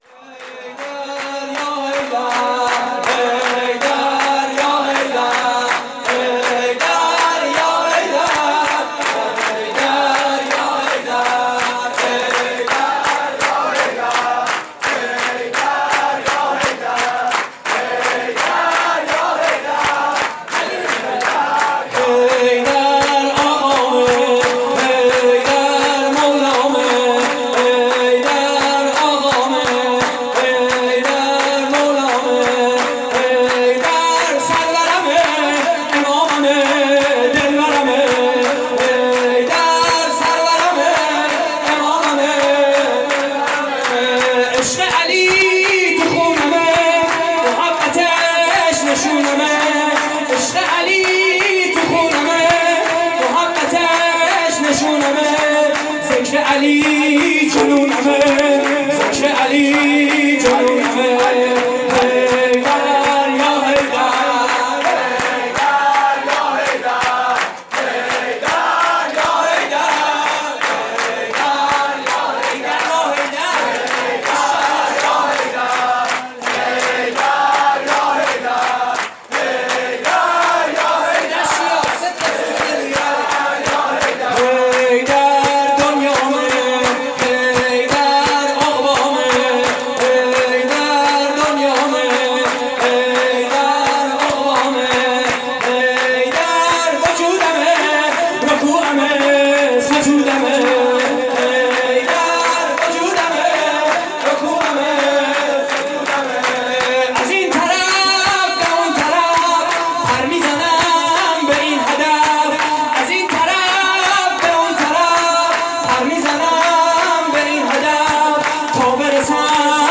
مراسم جشن ولادت حضرت امیر (ع)